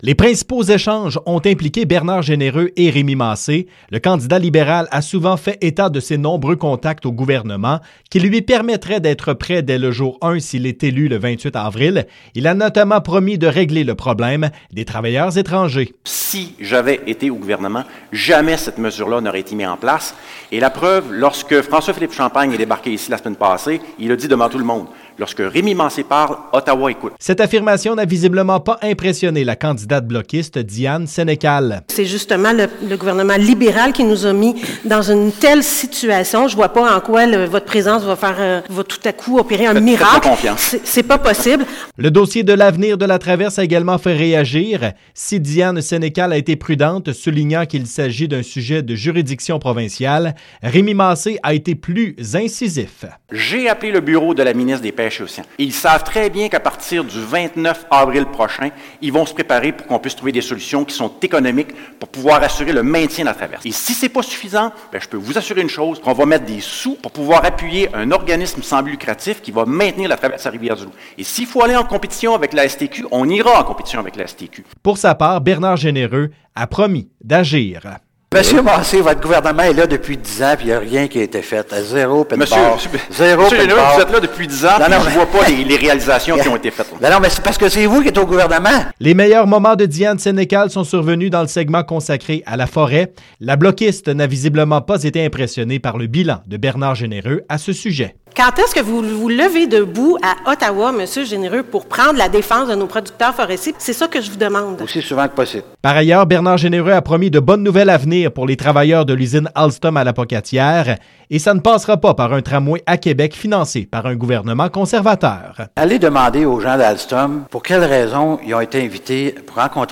Le débat des candidats dans le comté de Côte-du-Sud – Rivière-du-Loup – Kataskomiq – Témiscouata a eu lieu ce matin à l’Hôtel Universel.